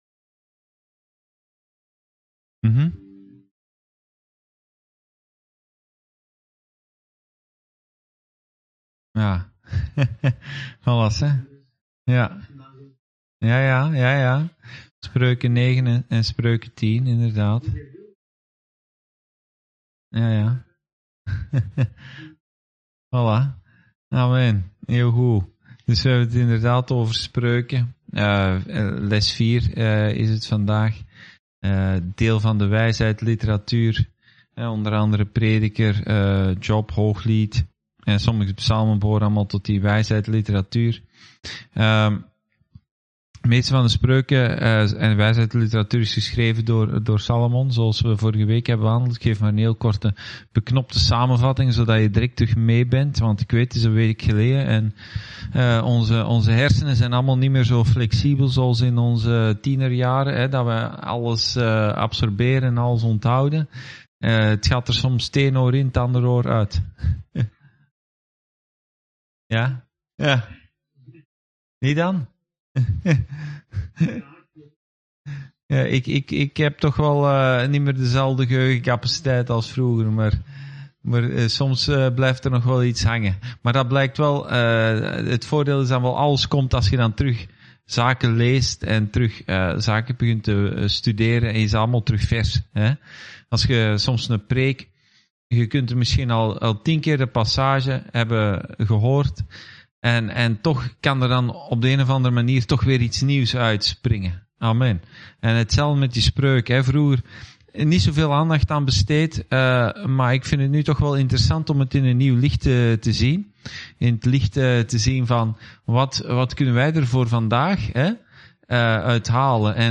Bijbelstudie: spreuken 9-10